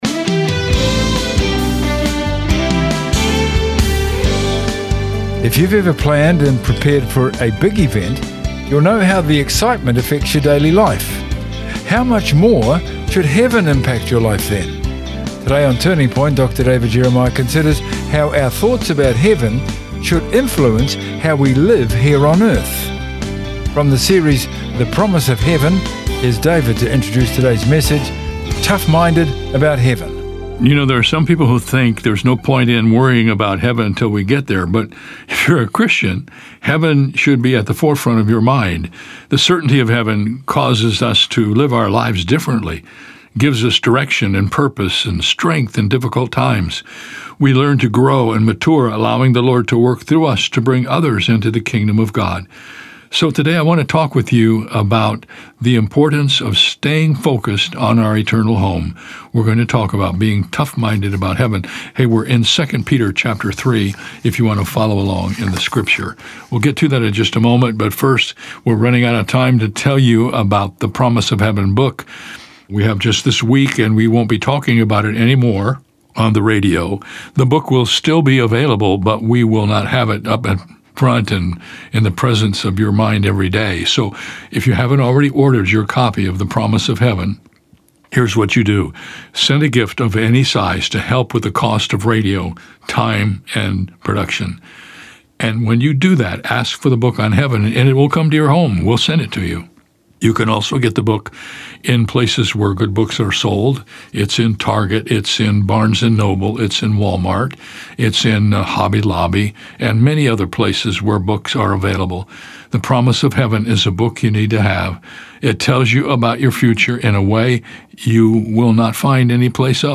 Dr. David Jeremiah is the senior pastor of Shadow Mountain Community Church in San Diego, California, and the founder of the international radio and television ministry Turning Point.